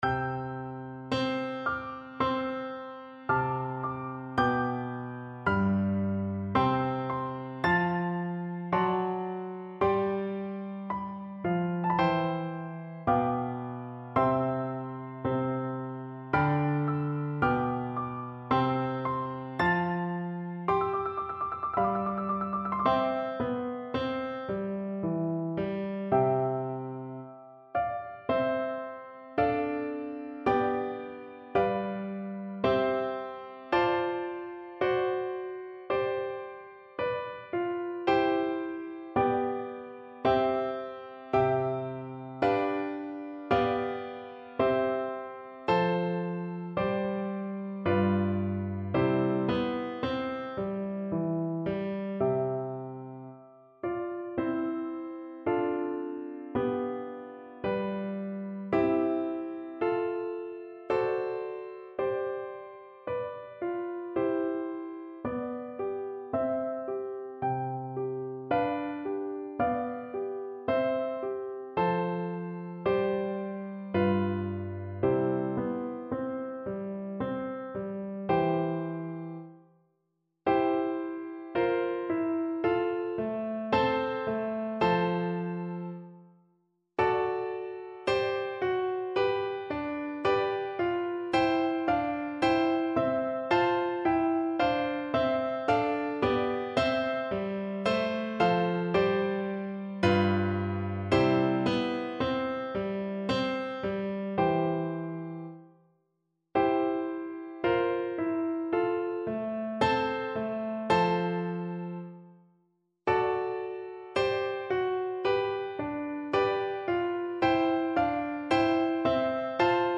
Flute version
Andante =80
3/4 (View more 3/4 Music)
Classical (View more Classical Flute Music)